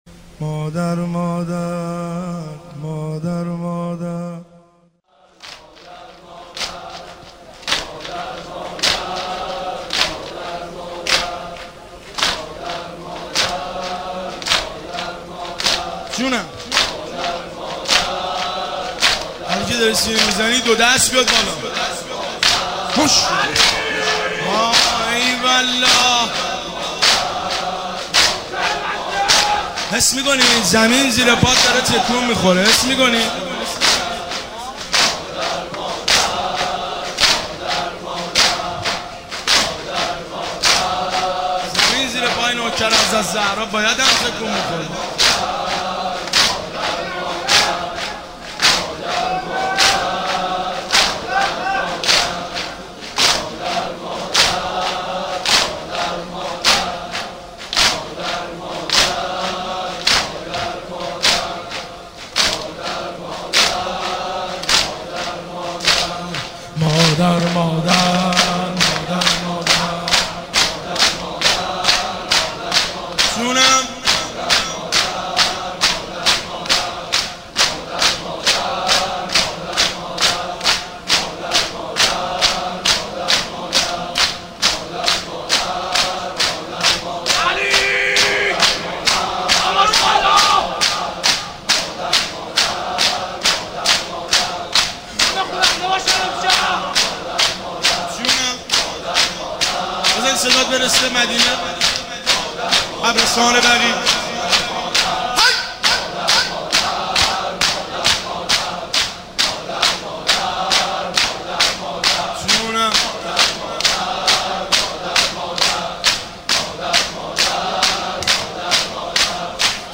ایام فاطمیه 1392 غریب مدینه امیرکلا
شادی هر دو جهان بی تو مرا جز غم نیست ( شعرخوانی و روضه امام حسین(ع) )